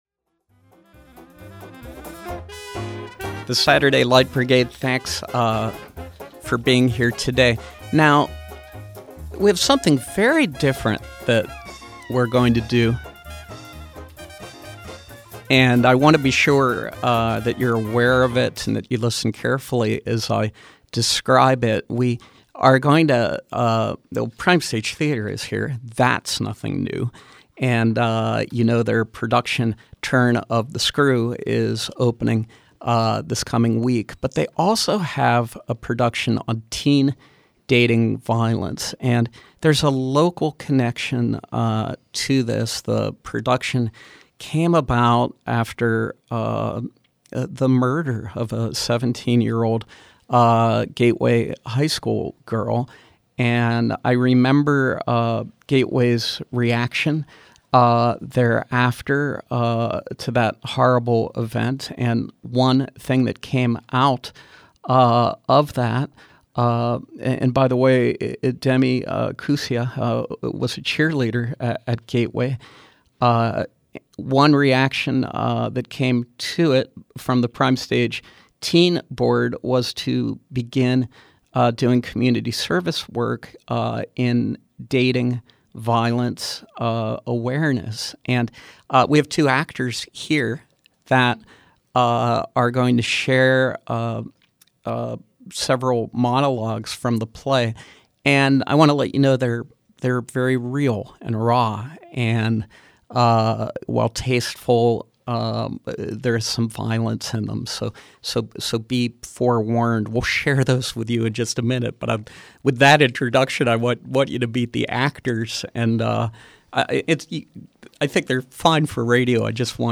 Scenes presented in this play contain mature themes and language.